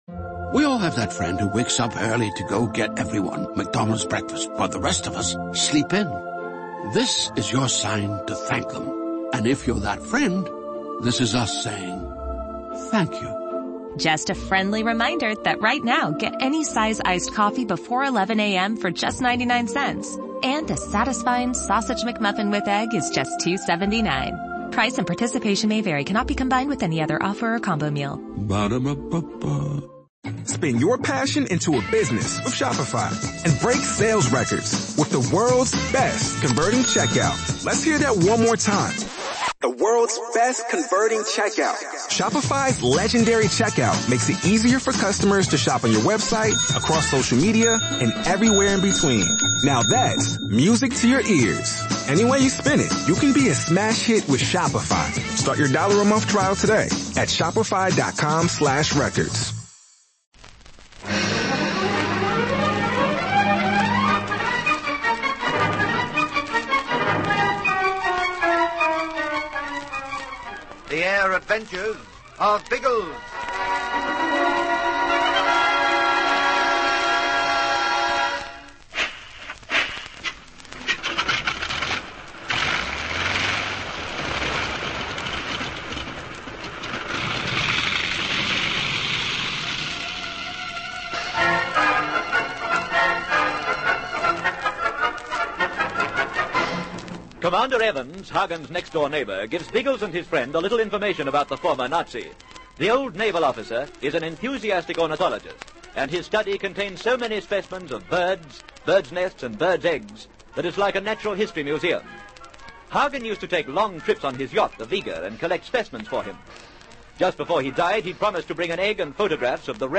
The Air Adventures of Biggles was a popular radio show that ran for almost a decade in Australia, from 1945 to 1954.
Biggles and his trusty companions, Ginger Hebblethwaite and Algy Lacey, soared through the skies in a variety of aircraft, from biplanes to jet fighters, taking on villains, rescuing damsels in distress, and generally having a whale of a time. The show was known for its exciting sound